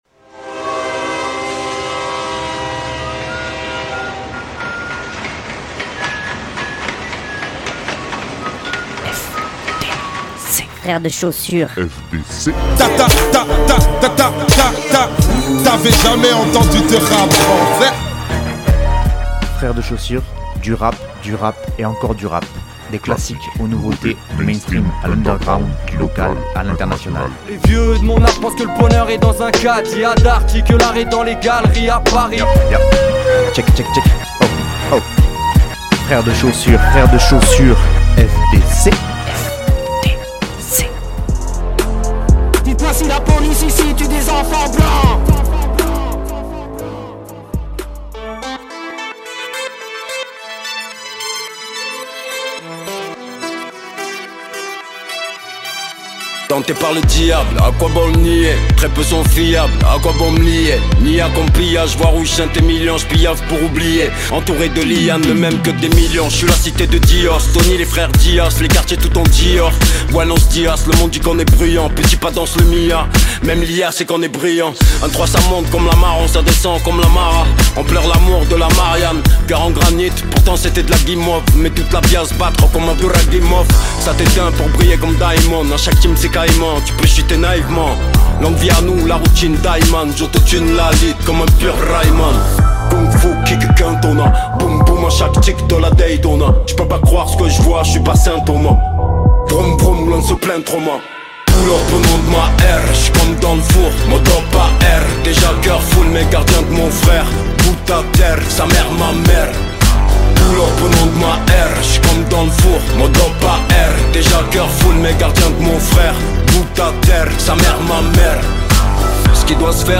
Beaucoup de rap encore hein ?